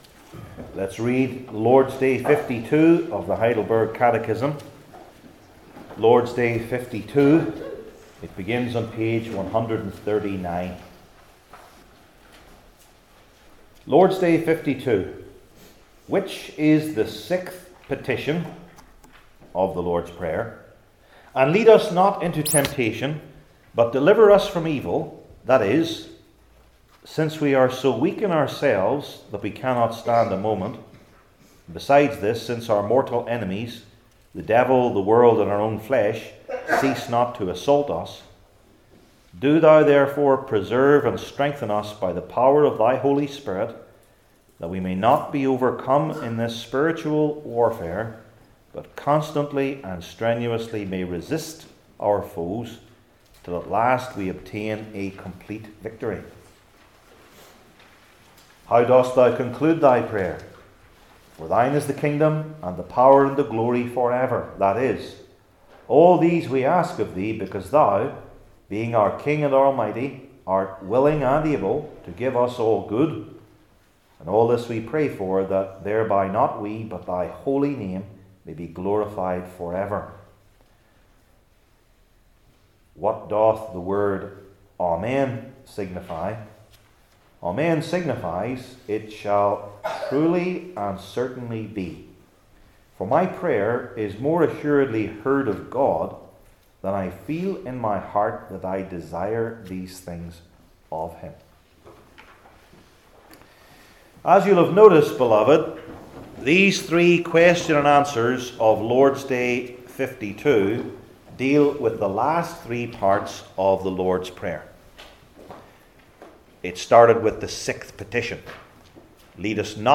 Daniel 2:31-47 Service Type: Heidelberg Catechism Sermons I. The Characteristics of the Kingdom II.